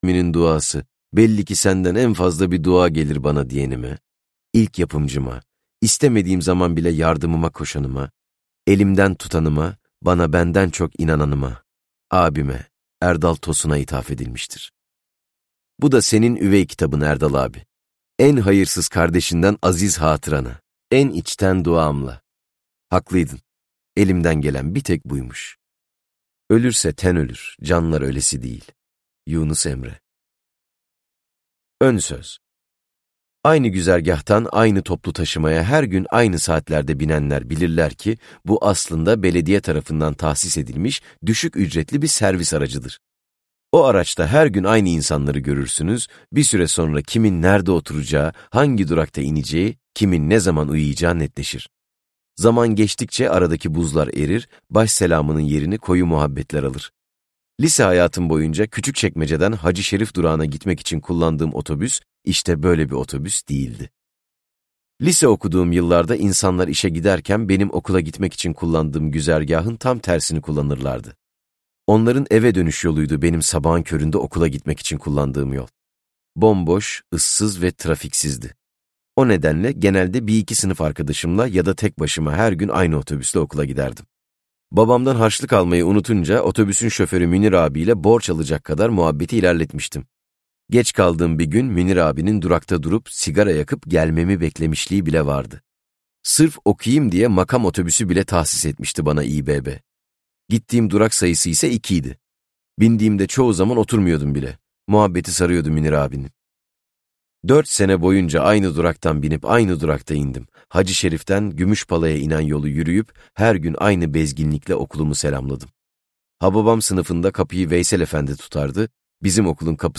Liseden Arkadaşlar - Seslenen Kitap